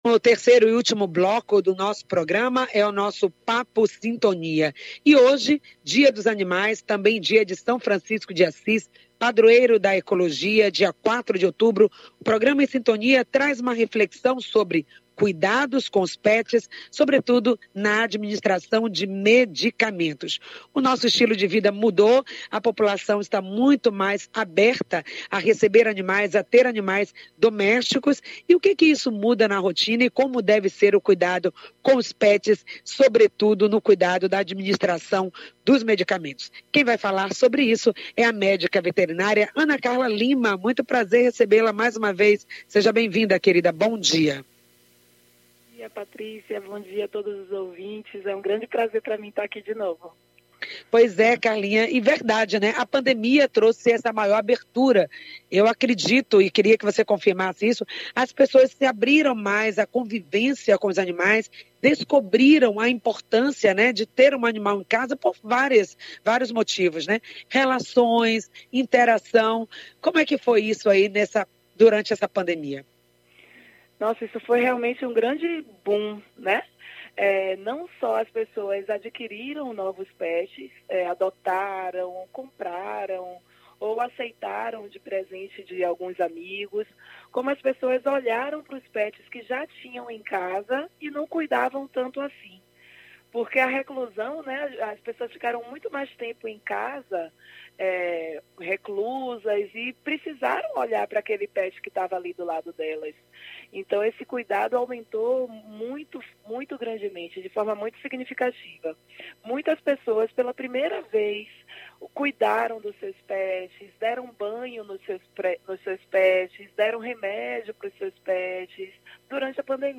O áudio da entrevista: https